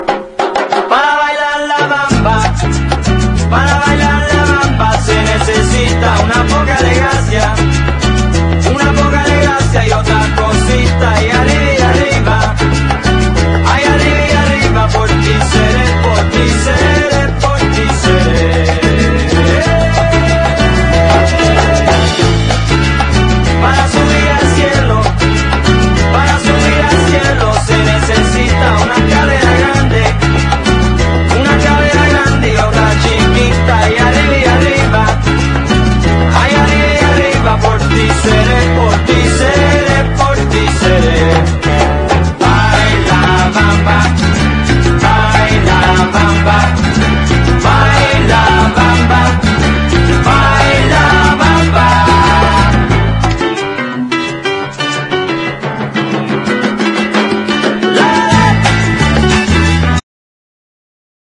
黒白男女混成ファンキー・ロック自主盤！